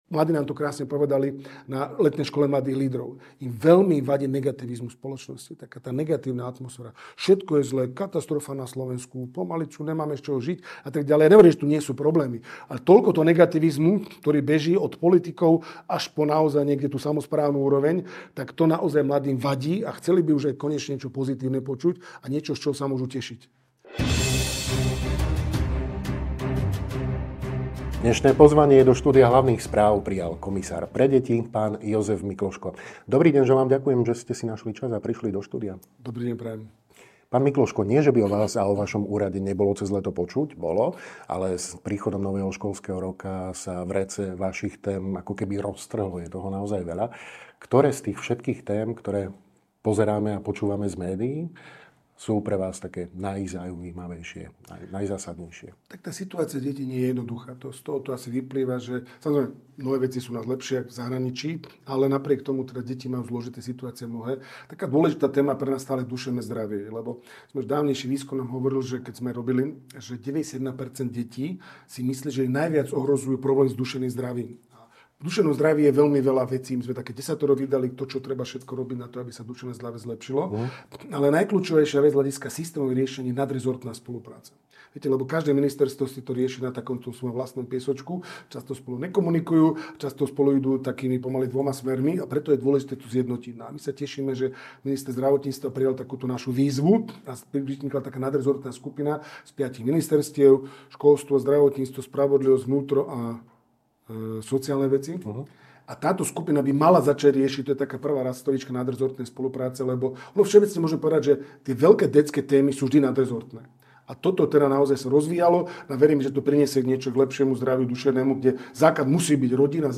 Dôležitosť rodinných vzťahov, odolnosť detí a ich aktívna účasť na riešení problémov, ale aj novela Ústavy SR, ktorá posilňuje práva detí v otázkach adopcií, aj náhradného materstva. Aj to boli témy videorozhovoru s komisárom pre deti, Prof. MUDr. Jozefom Mikloškom, PhD.